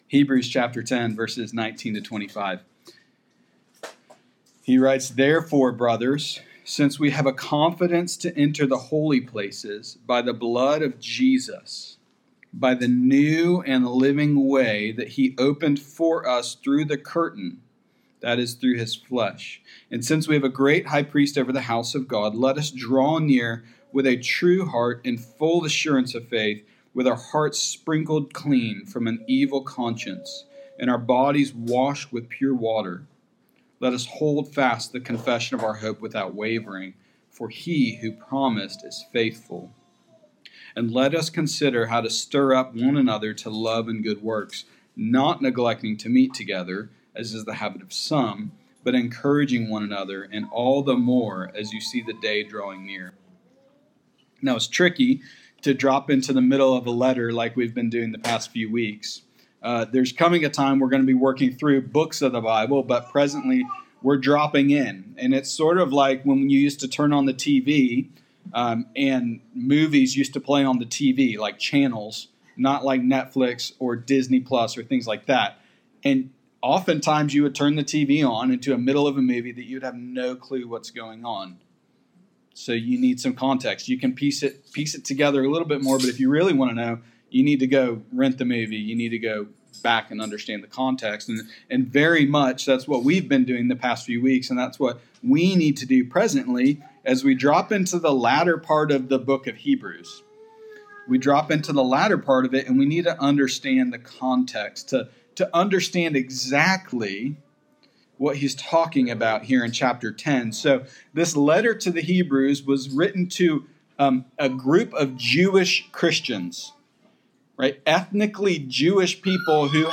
Sermons | Pillar Community Church